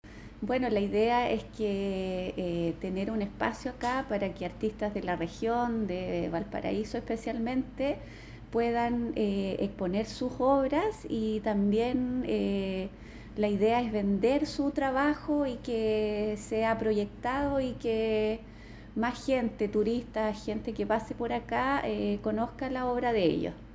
Inauguración de Cafetería Rapelli